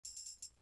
Crashes & Cymbals
Neighborhood Watch Cymbal.wav